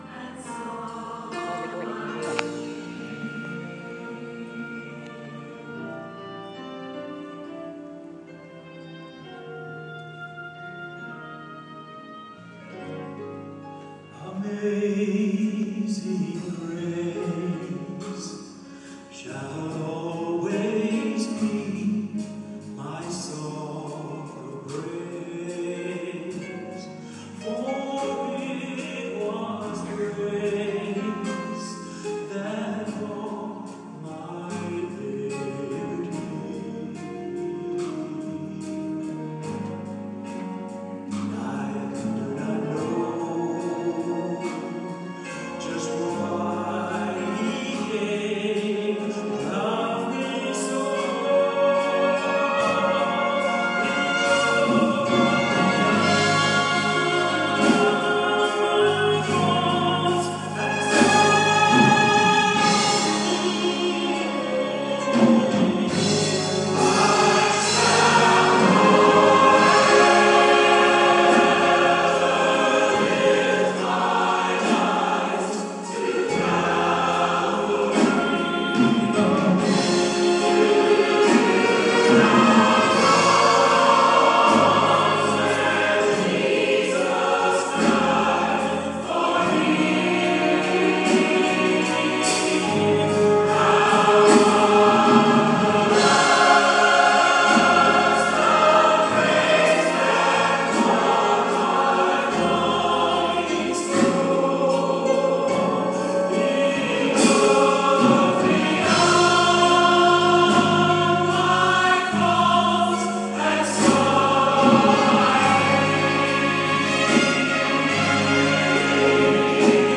A taste of choir today